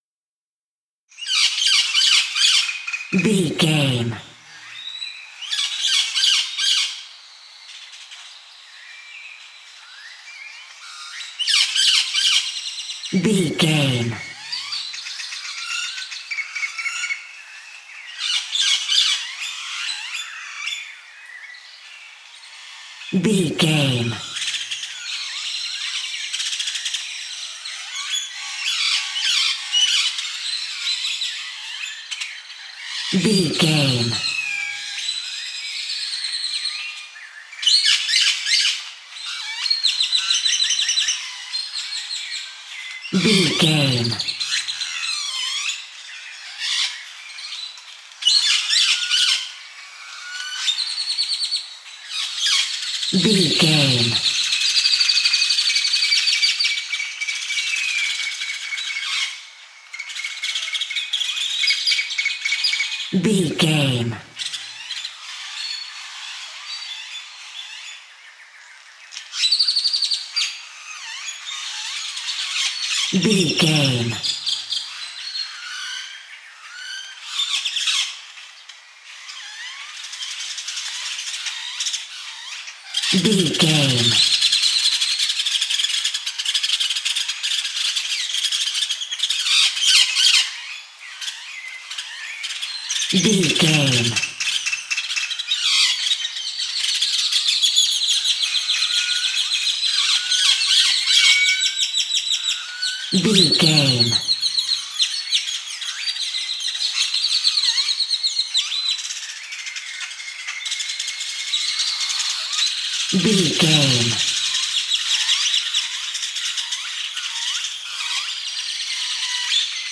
Country ambience morning birds
Sound Effects
calm
nature
peaceful
repetitive
ambience